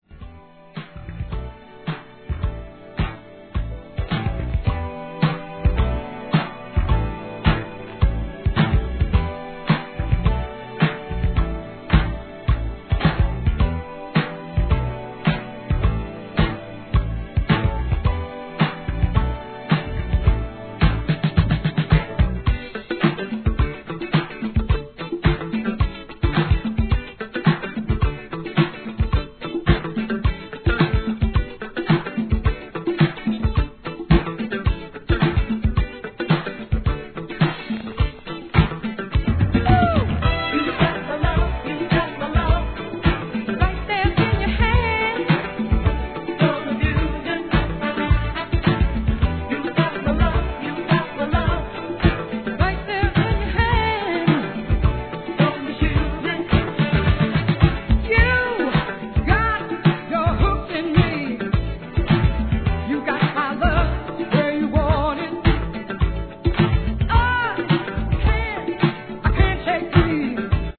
1. SOUL/FUNK/etc...
美メロ・イントロから、軽快なギターが爽快な1981年DANCE CLASSIC!!